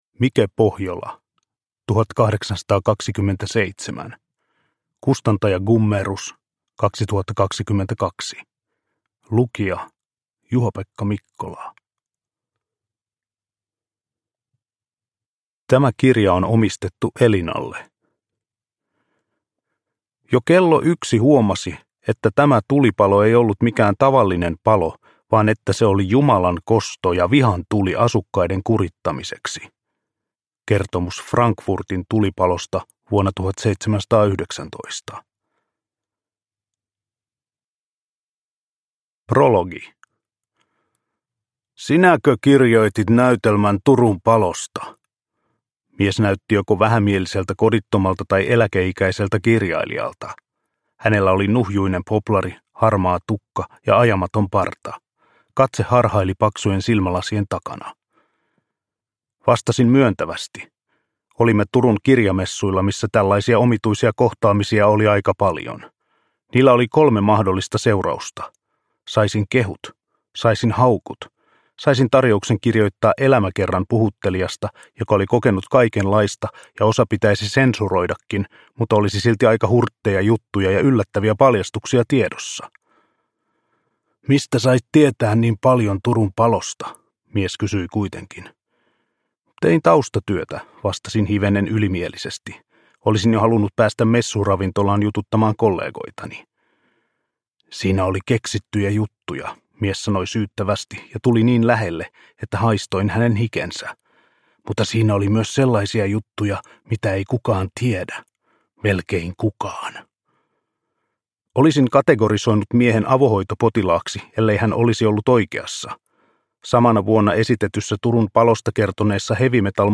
1827 – Ljudbok – Laddas ner